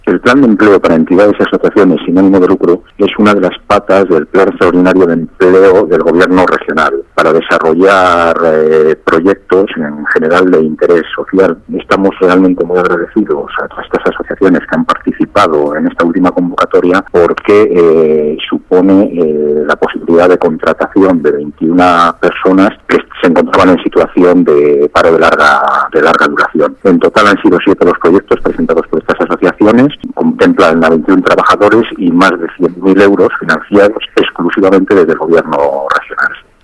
El director provincial de Economía, Empresas y Empleo en Guadalajara, Santiago Baeza, habla del Plan de Empleo para ESAL.